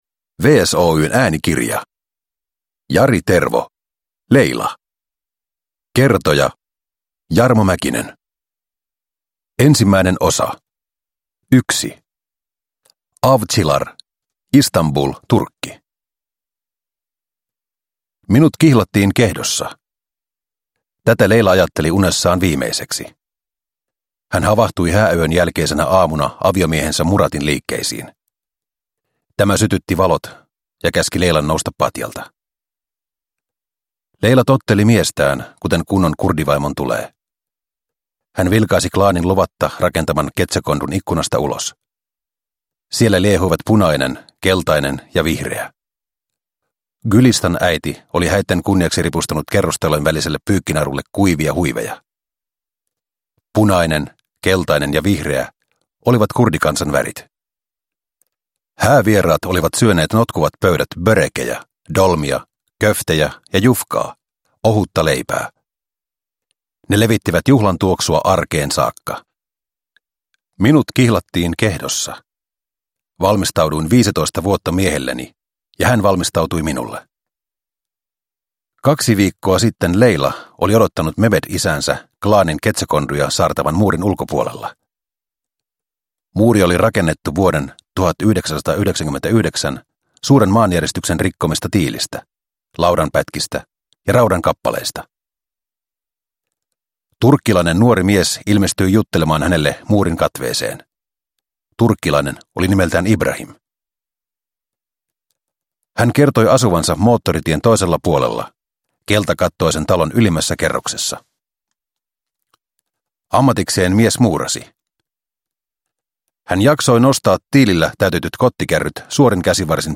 Layla – Ljudbok – Laddas ner